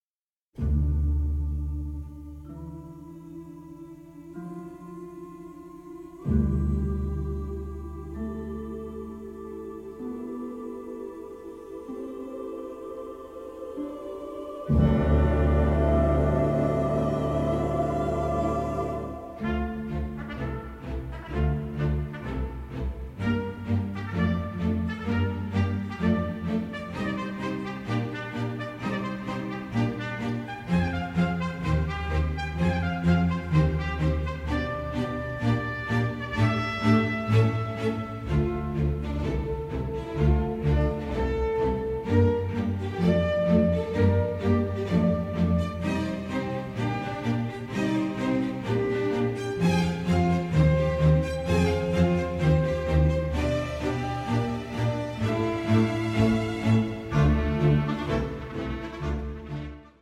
ALBUM STEREO TRACKS